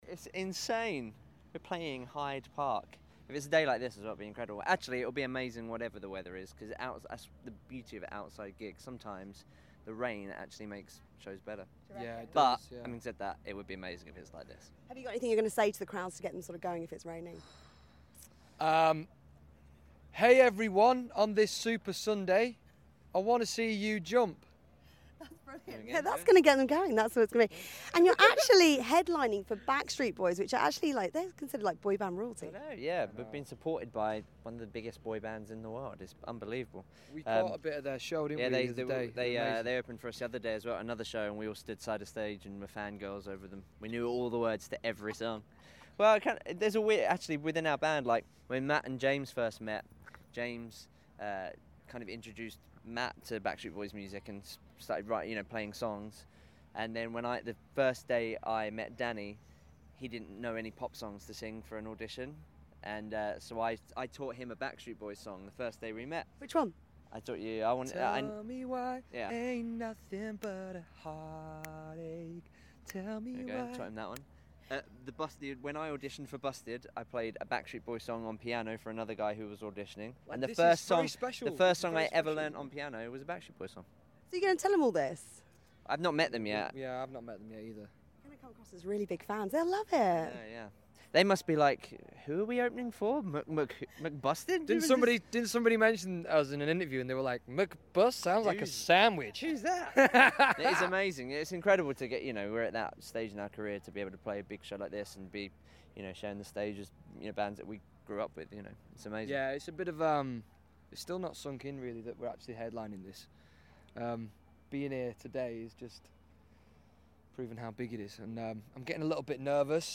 McBusted on British Summertime Live - Full Interview